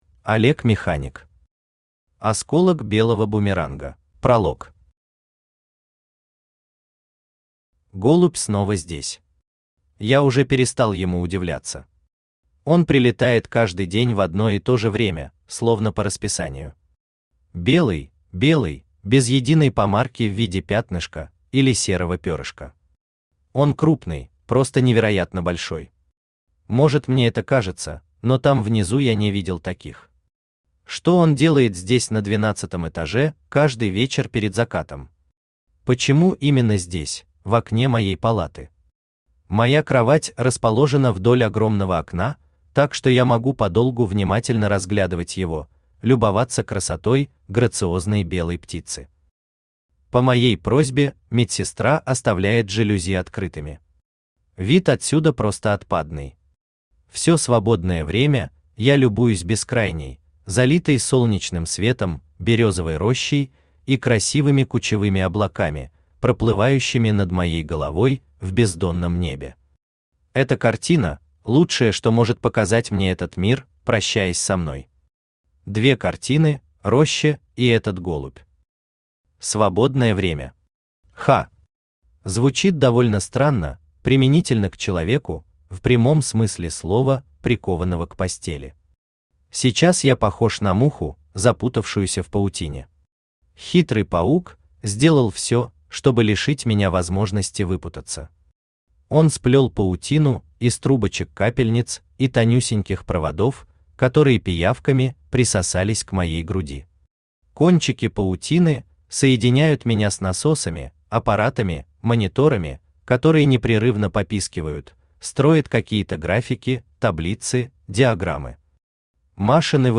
Аудиокнига Осколок белого бумеранга | Библиотека аудиокниг
Aудиокнига Осколок белого бумеранга Автор Олег Механик Читает аудиокнигу Авточтец ЛитРес.